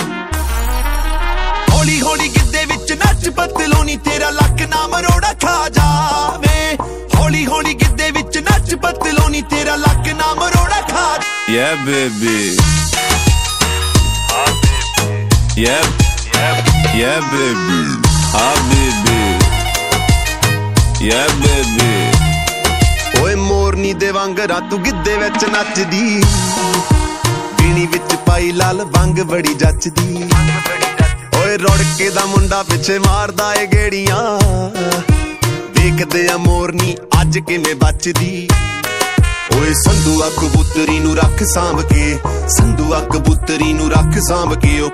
Category: Punjabi Ringtones